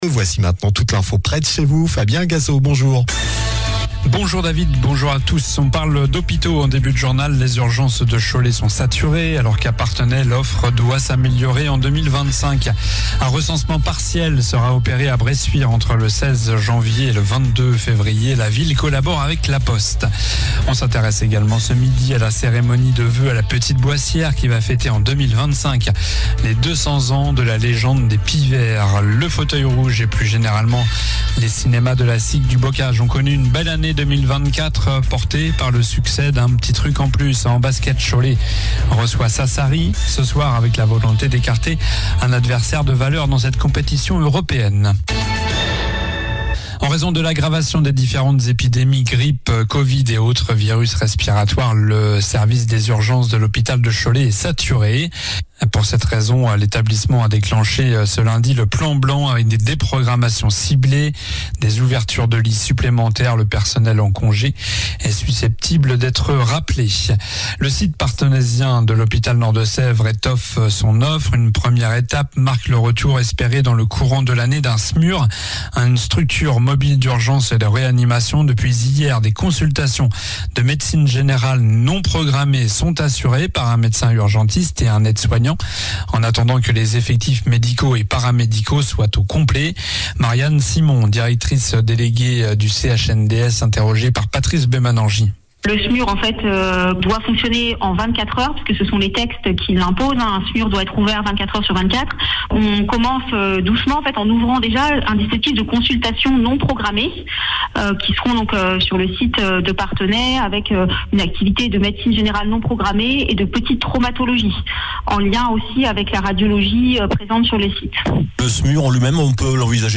Journal du mardi 07 janvier (midi)